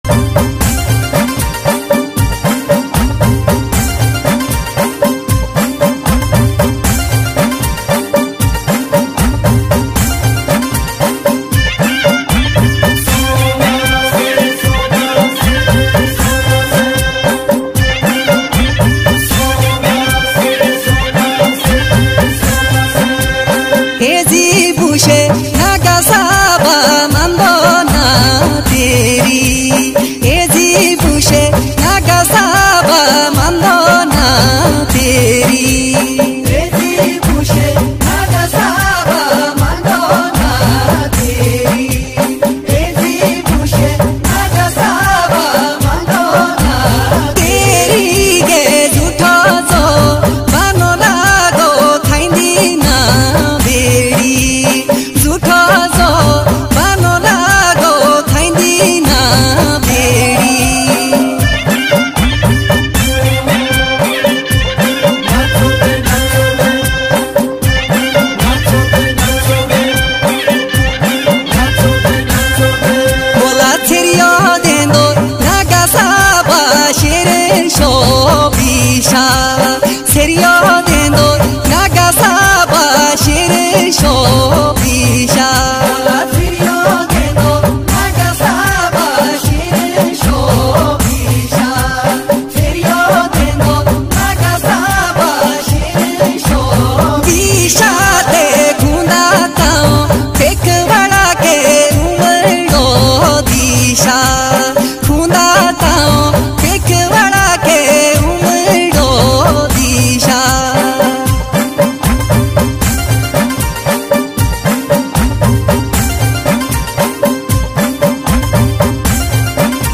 Himachali Songs